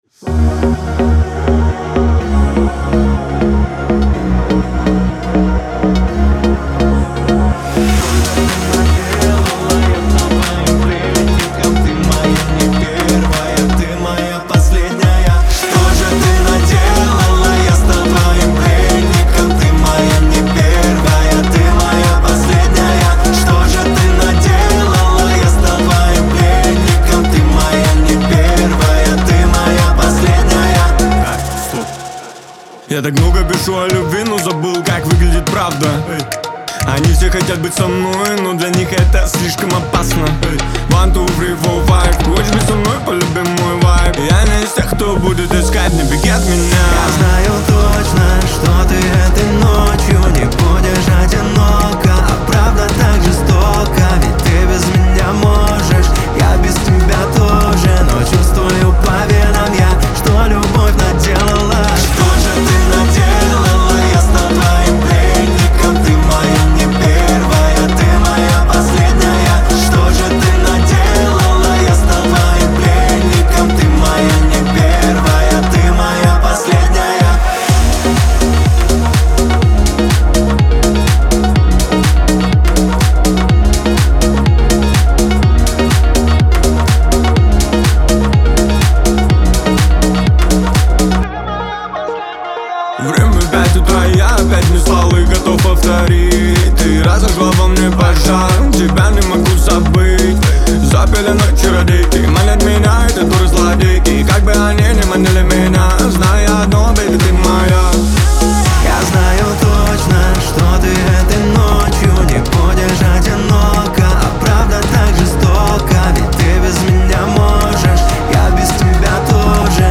дуэт
грусть , Лирика